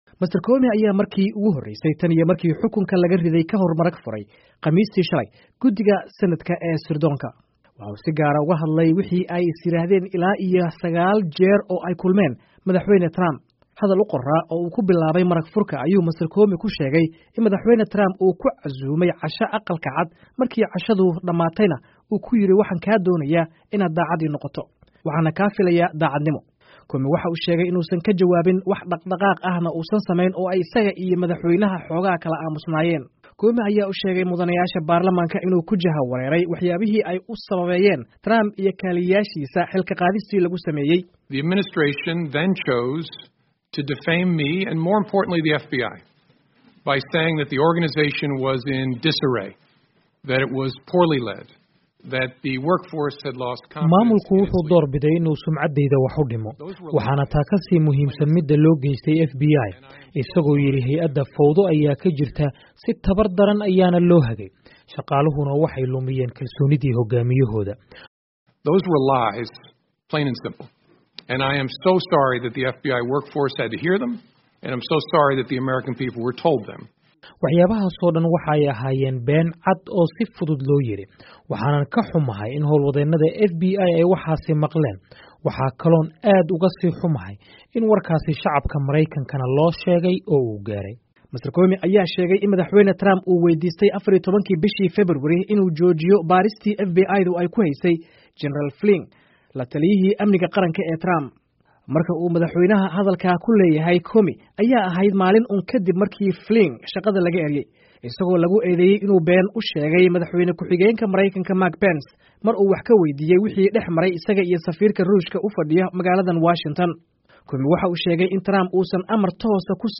Warbixin: Maragfurka Comey